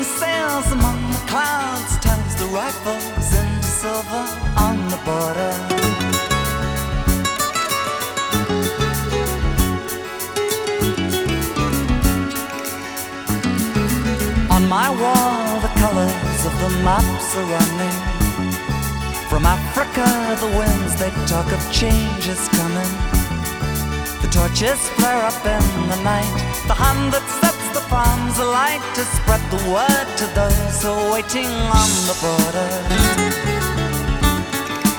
Folk-Rock
Жанр: Поп музыка / Рок / Фолк